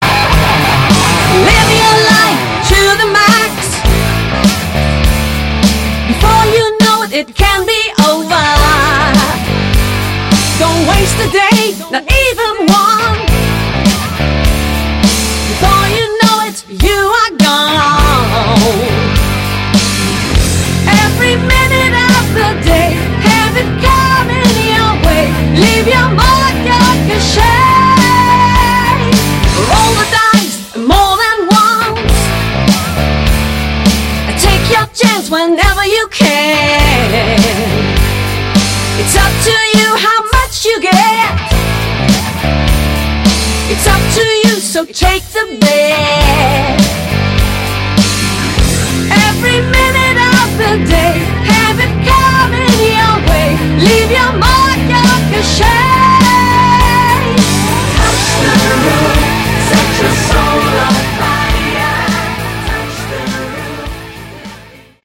Category: Melodic Rock
vocals
bass
guitar
drums
keyboards